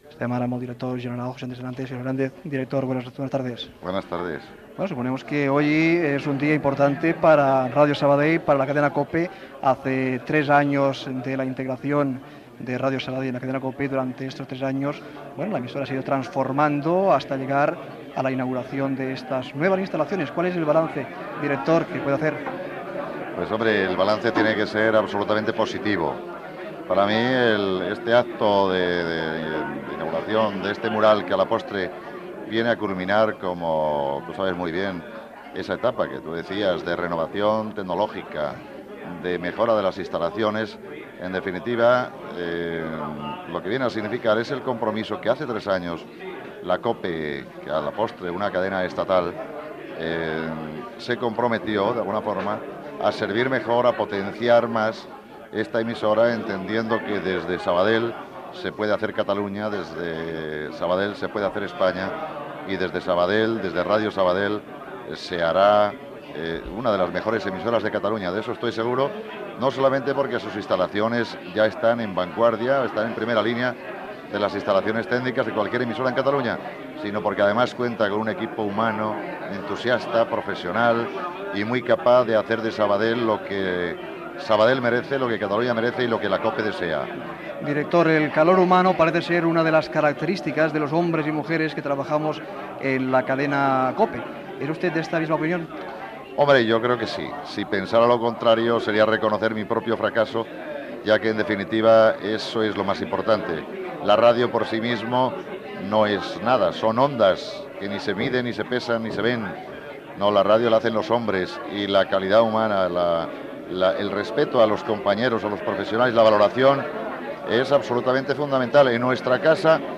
Edició especial amb motiu de la inauguració de la remodelació dels estudis de Ràdio Sabadell.
Entreteniment